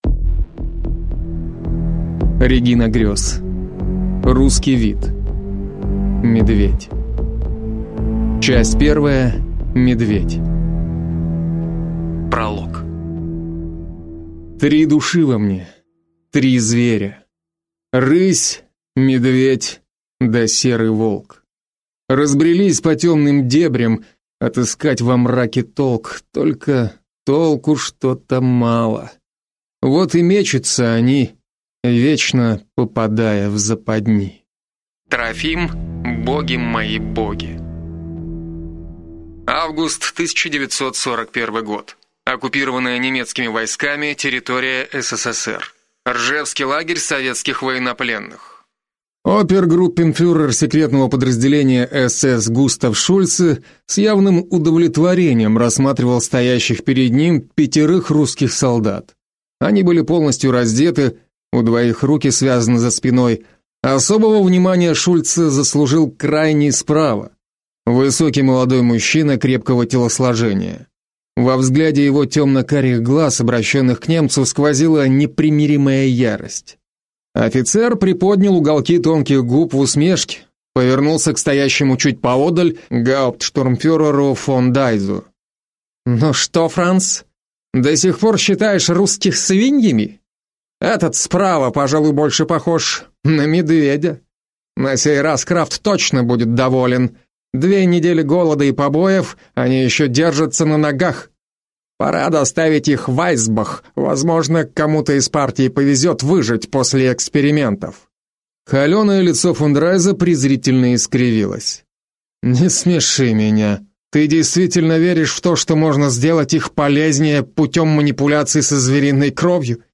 Aудиокнига Русский вид. Медведь Автор Регина Грёз